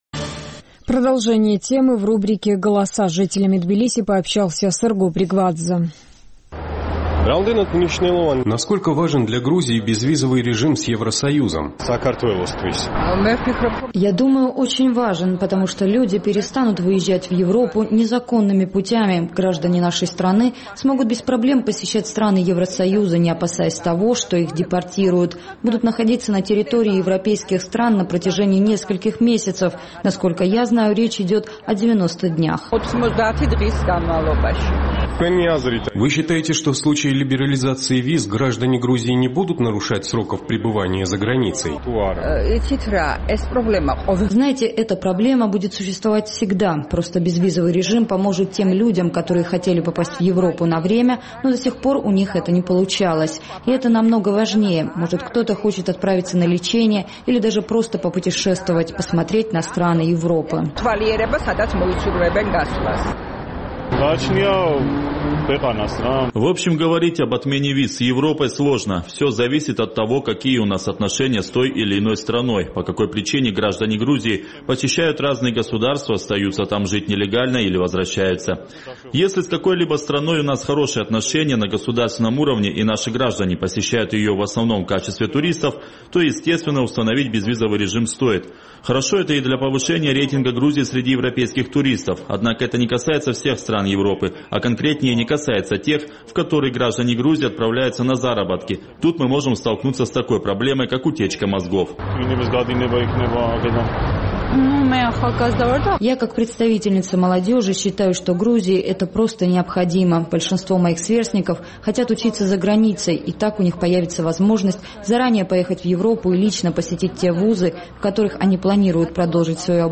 Наш тбилисский корреспондент решил поинтересоваться мнением жителей столицы, как может повлиять на Грузию отмена виз с ЕС.